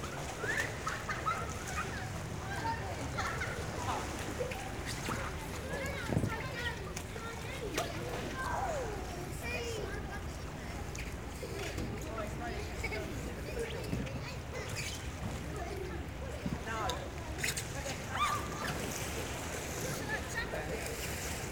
pool.wav